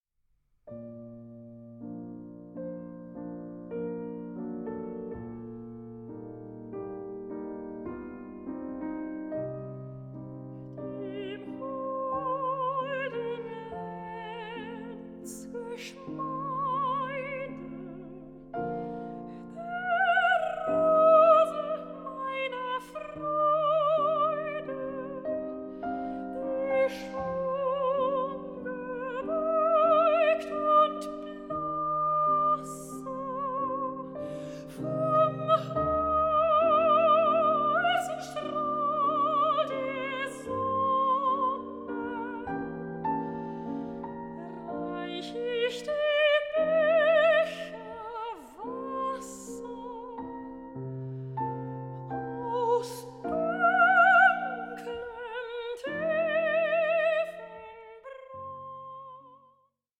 soprano
pianist